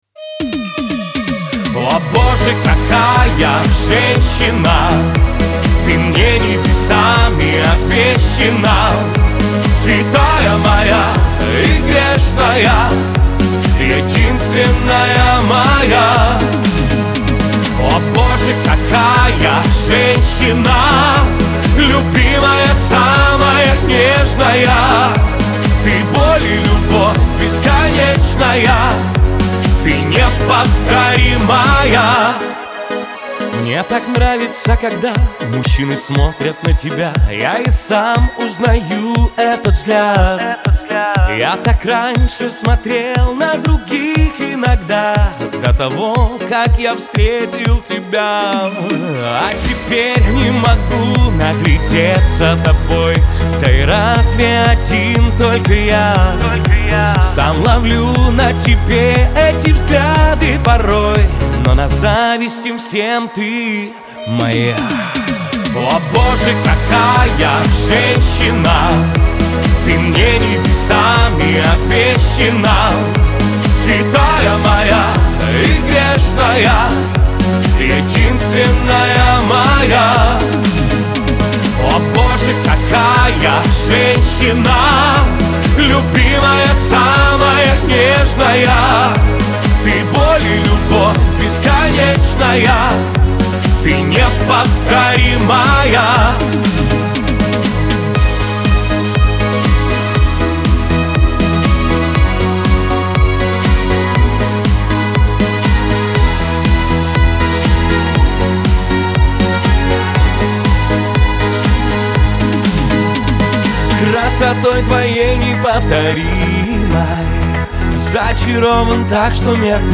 эстрада
ХАУС-РЭП